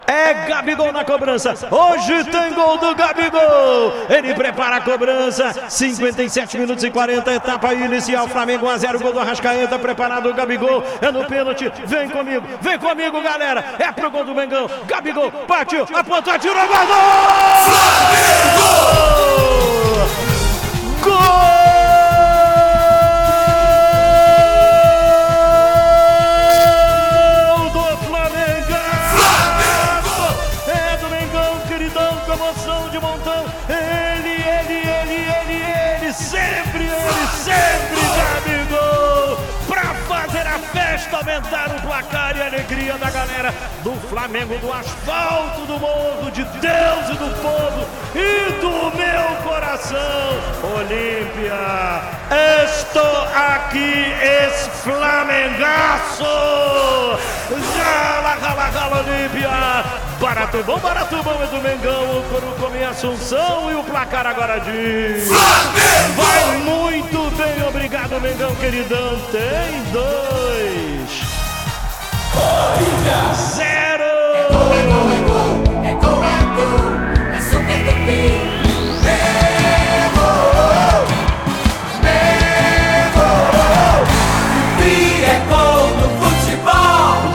Ouça os gols da vitória do Flamengo sobre o Olimpia com a narração de Luiz Penido